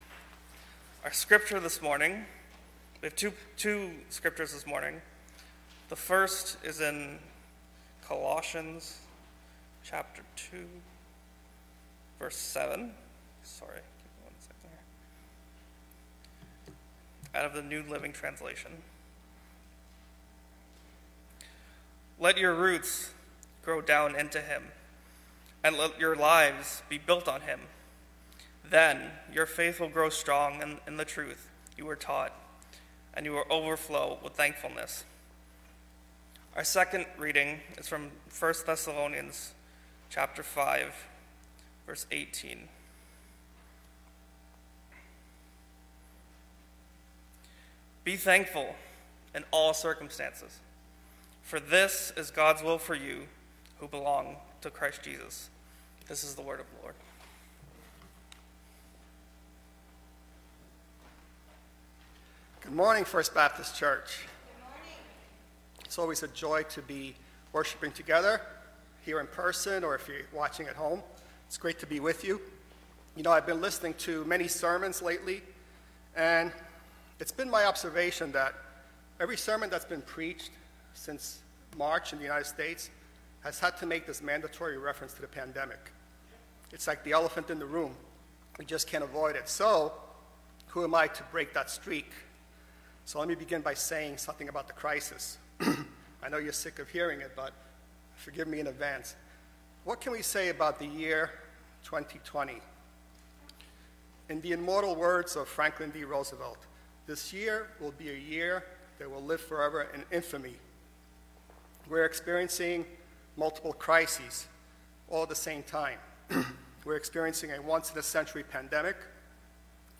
English Sermons | First Baptist Church of Flushing